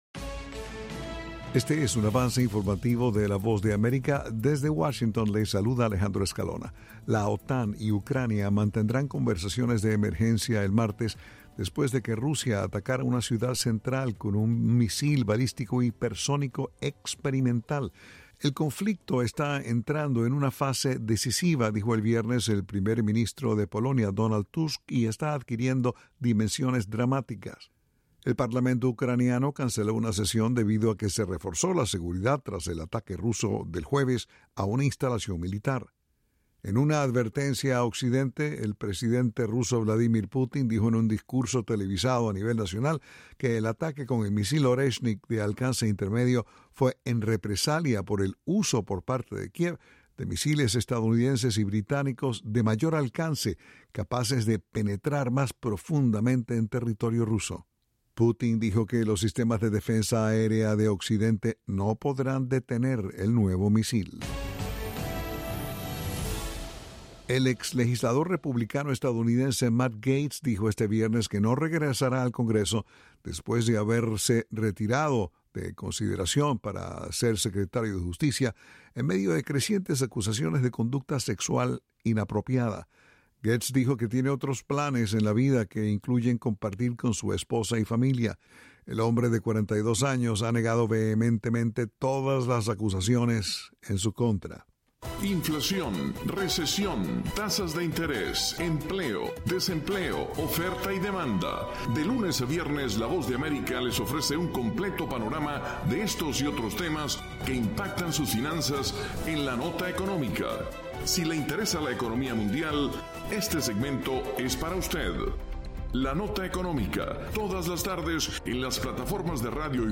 El siguiente es un avance informativo de la Voz de Aéerica.